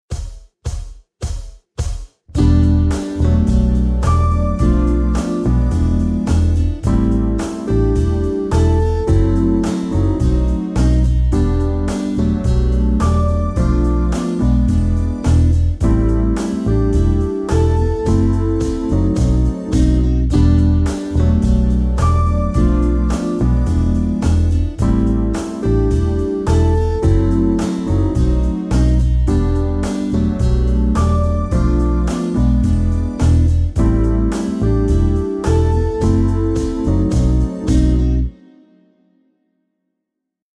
Akkordprogression med modulation og gehørsimprovisation:
Lyt efter bassen, der ofte spiller grundtonen.
Modulation en kvart op/kvart ned
C instrument (demo)